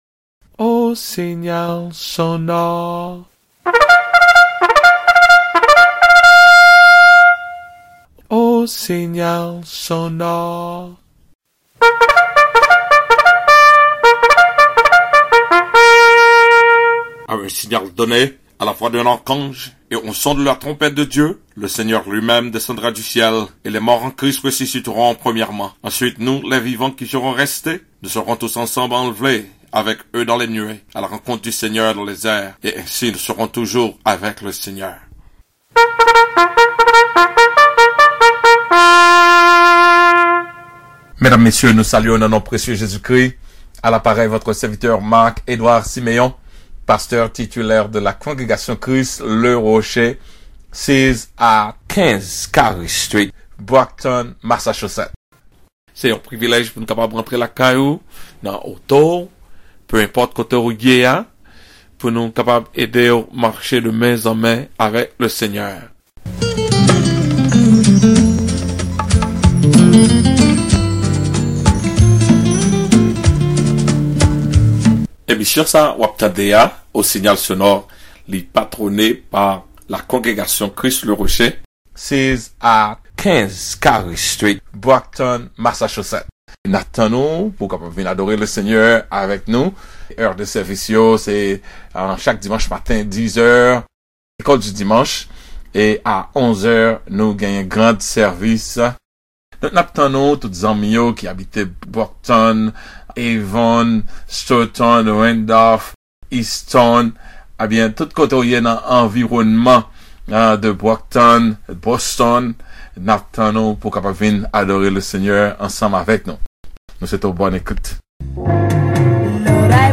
CLICK HERE TO DOWNLOAD THE SERMON: SOMMAIRE SUR LA PERSONNE DU SAINT ESPRIT